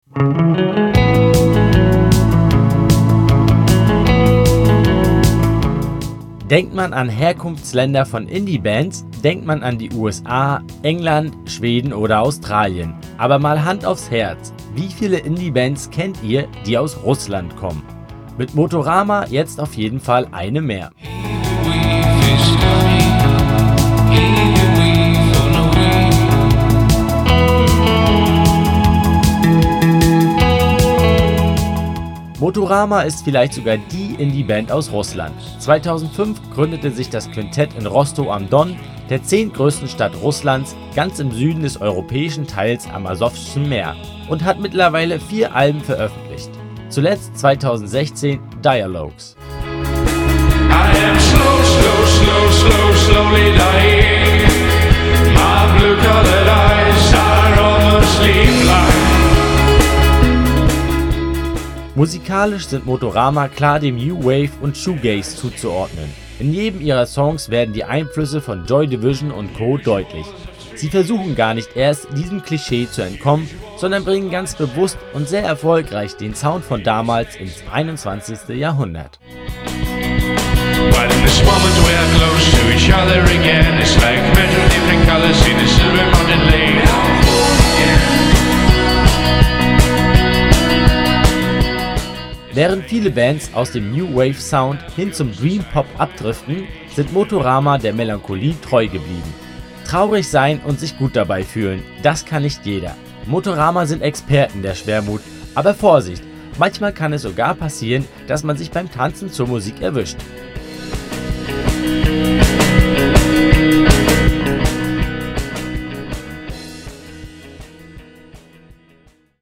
Die Musik des russischen Quintetts orientiert sich am am Post-Punk der 1980er Jahre.